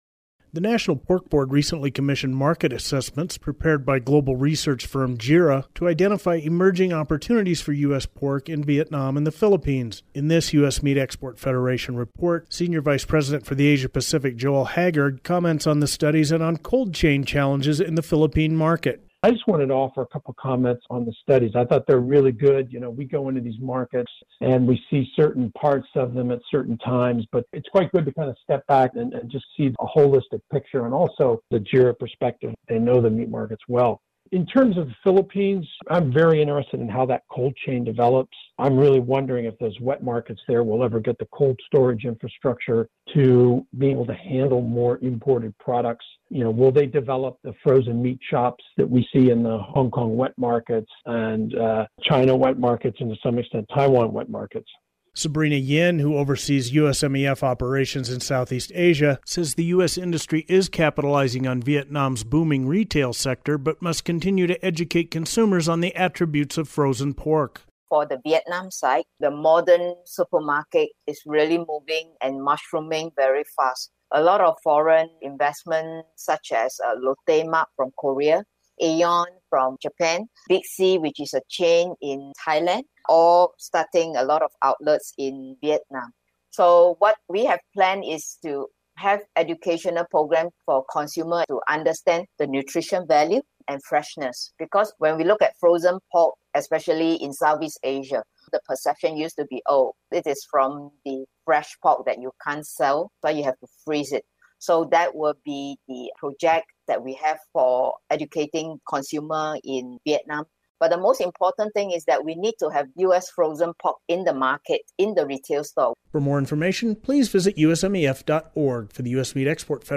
In this audio report